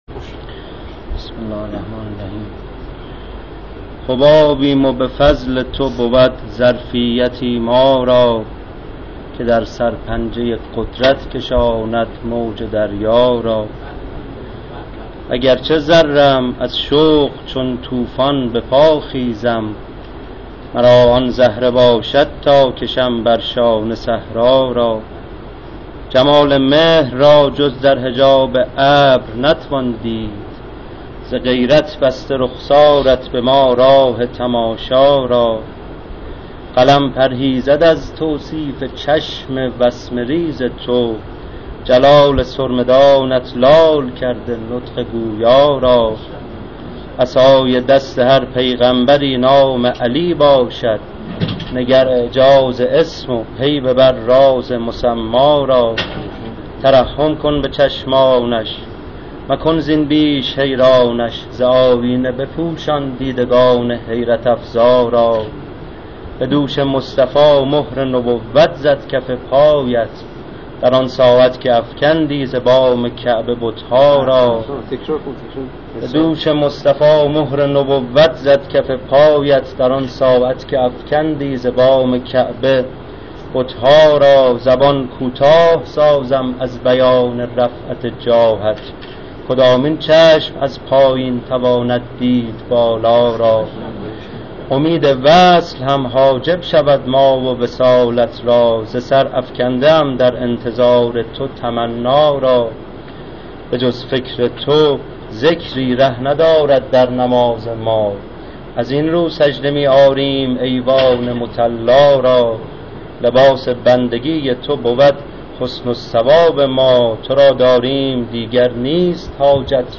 در ادامه متن اشعار و صوت شعر خوانی شعرا را مشاهده می کنید: